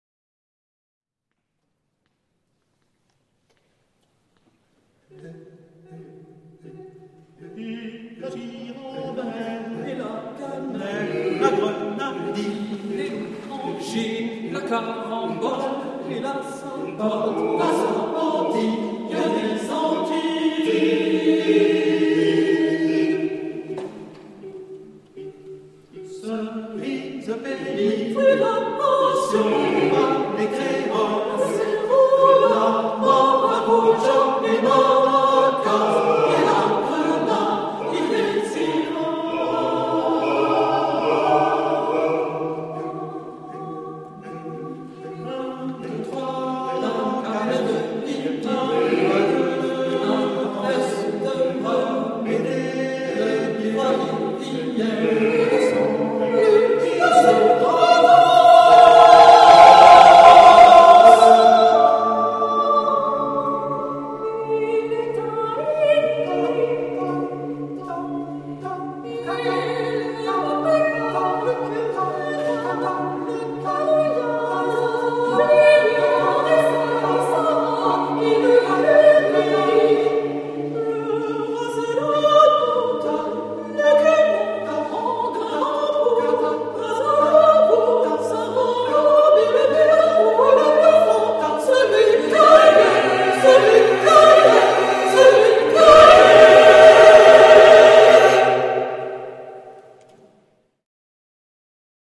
pour 12 voix mixtes a capella
l’enregistrement est une trace du concert de création